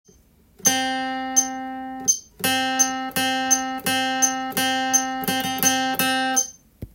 ２拍伸ばしてカスタム
譜面は全てドの音だけで表記していますので
３拍目から裏拍の連続という一番難易度が高いリズムになっています。